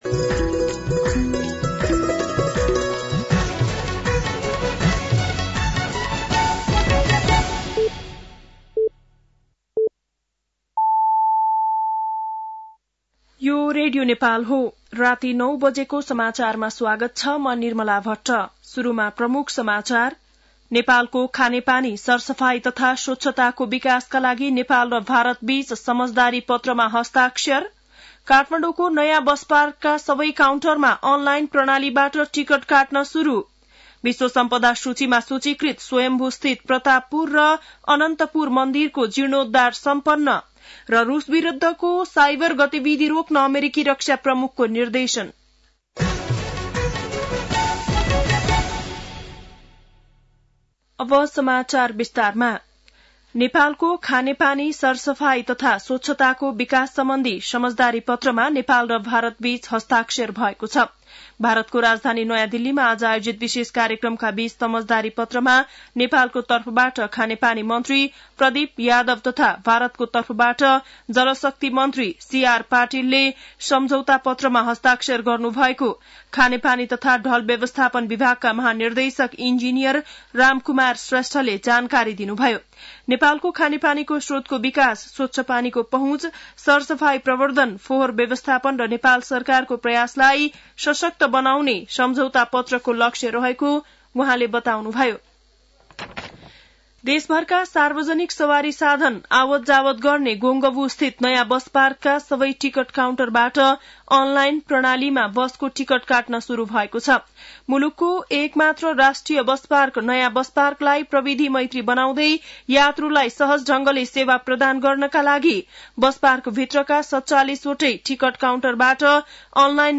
बेलुकी ९ बजेको नेपाली समाचार : २० फागुन , २०८१